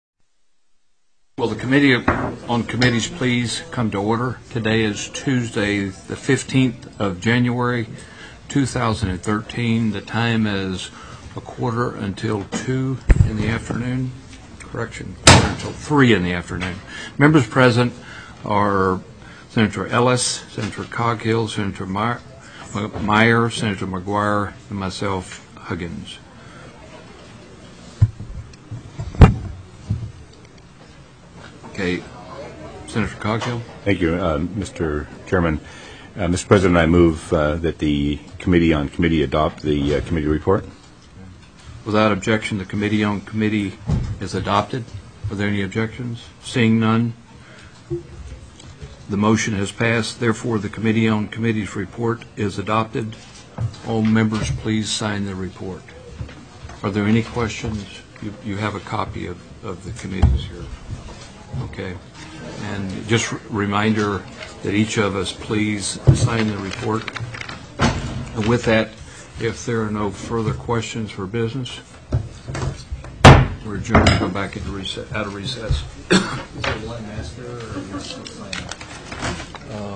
01/15/2013 02:45 PM Senate CCM